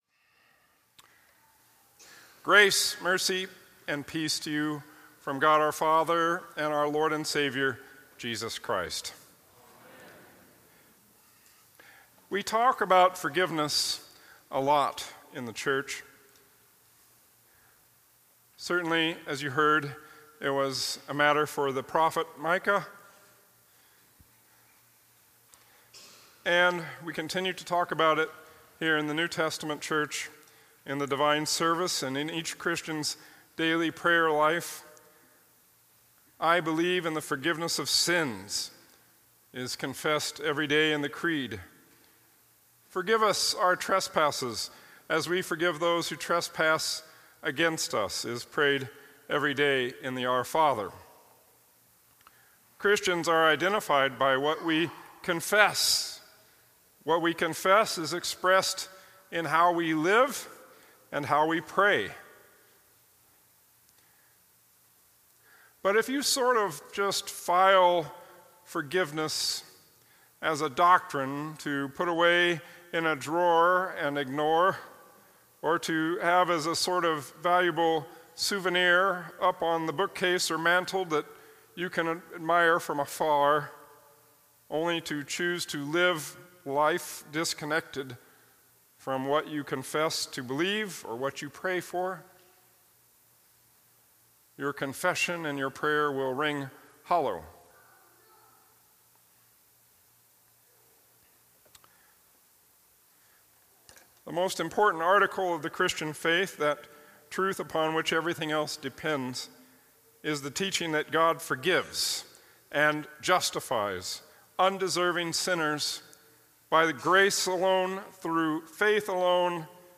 Twenty-Second Sunday after Trinity Observance of the Reformation, 1517-2018